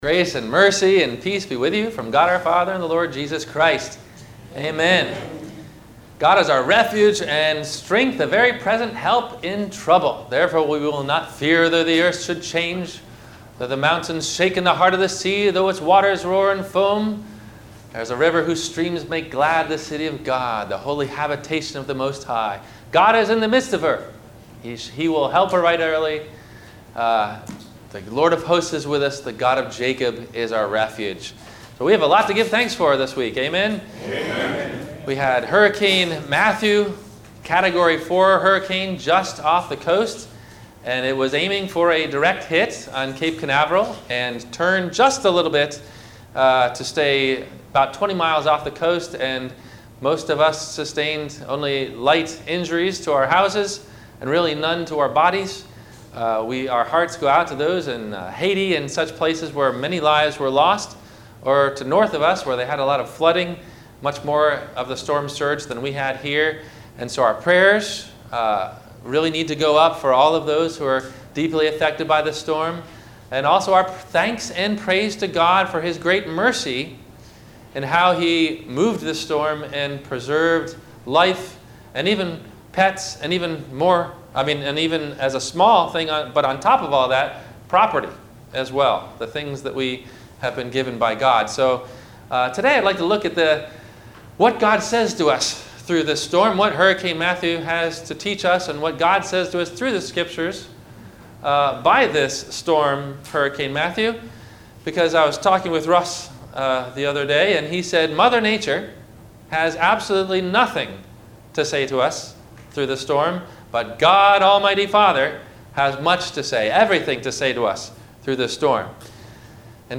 God Our Shelter from the Storm - Hurricane Matthew - Sermon - October 09 2016 - Christ Lutheran Cape Canaveral